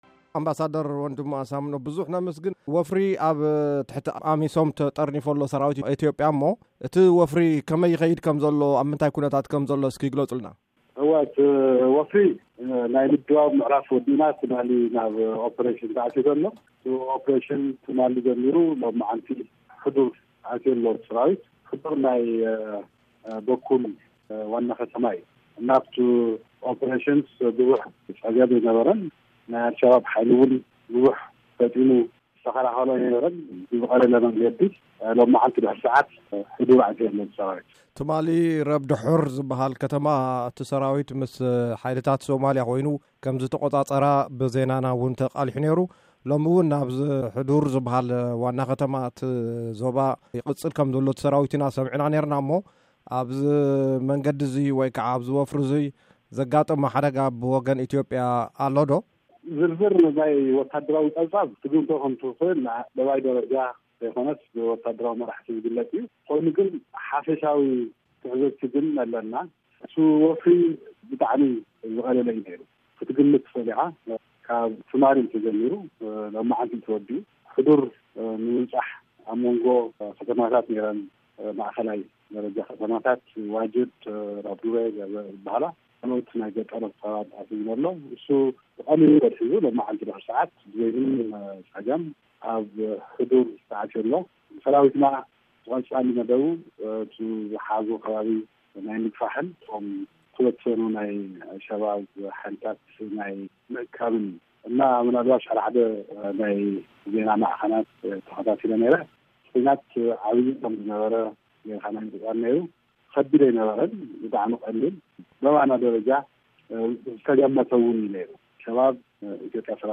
ቃለ-ምልልስ ምስ ኣምባሳደር ወንድሙ